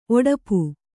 ♪ oḍapu